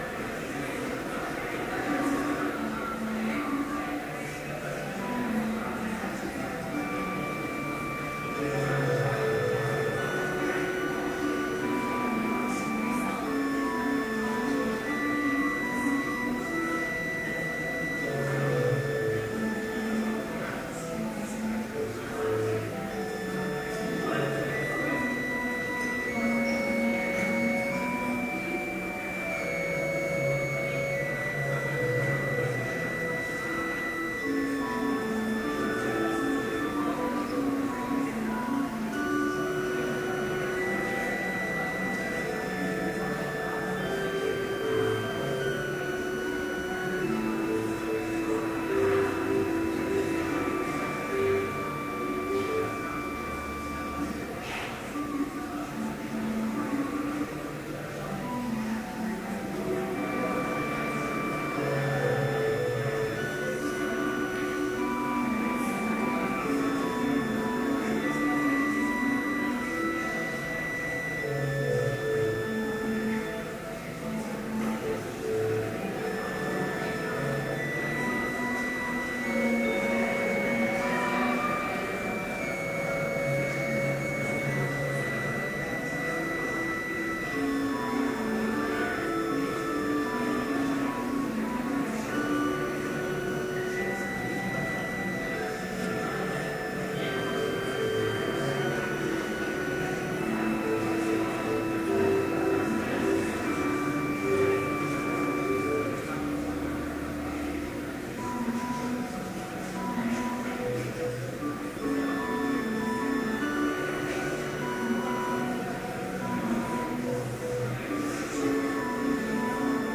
Complete service audio for Chapel - January 16, 2013